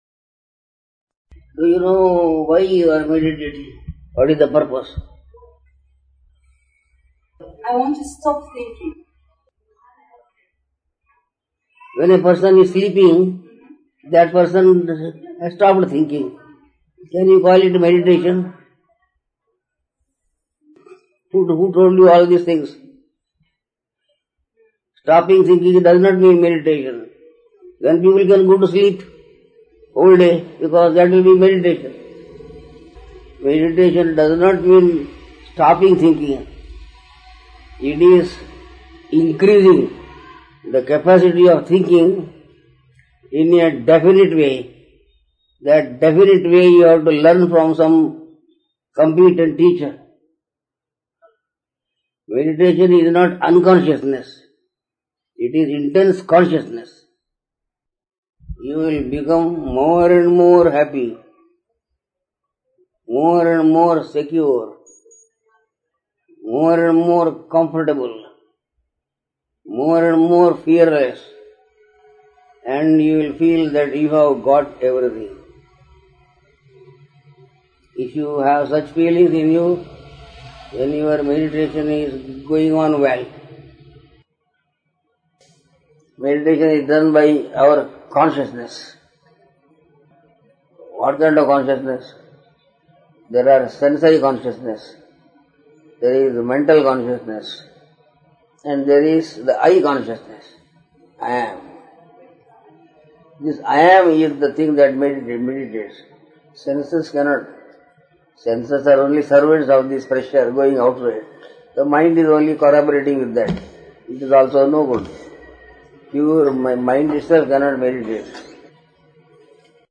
Darshan of Swami Krishnananda in 1998